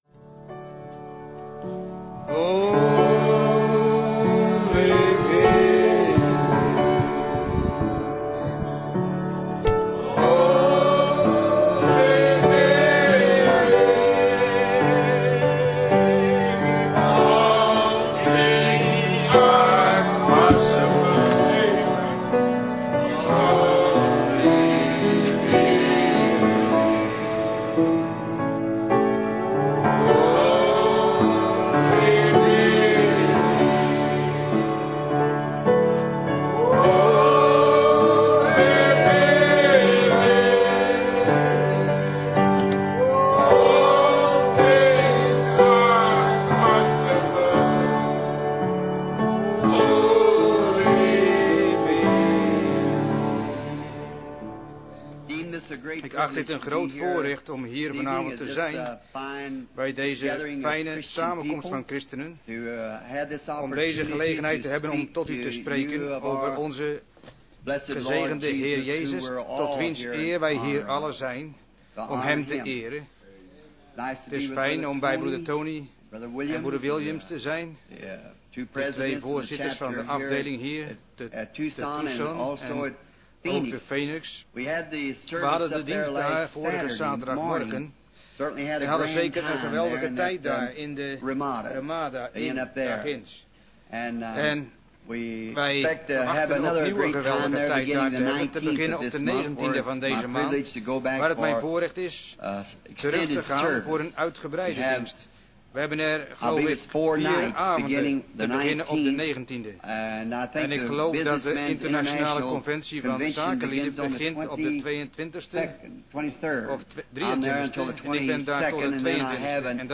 Prediking